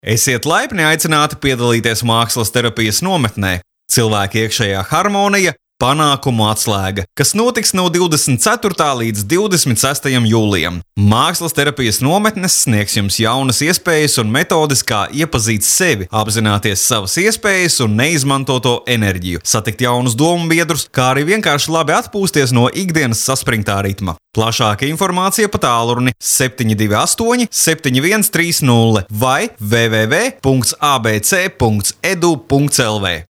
Sprecher lettisch für TV / Rundfunk / Industrie / Werbung.
Sprechprobe: Werbung (Muttersprache):
Professionell voice over artist from Latvia.